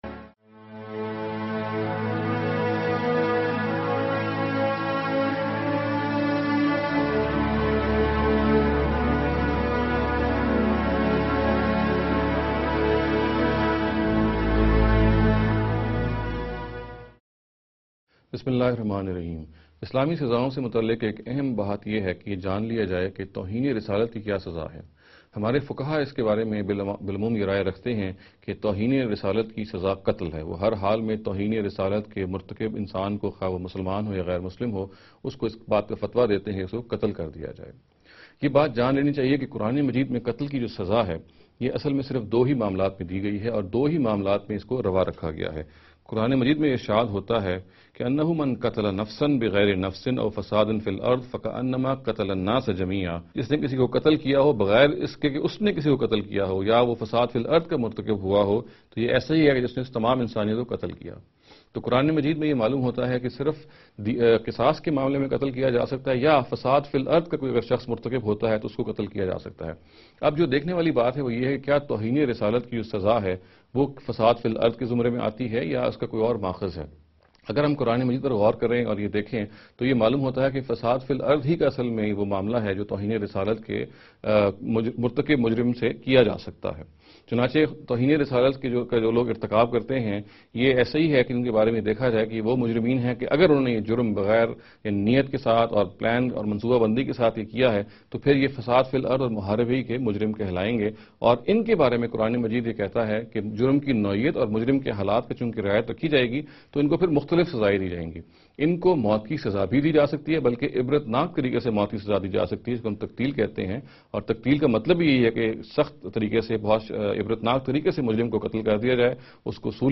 This lecture series will deal with some misconception regarding the Islamic Punishments.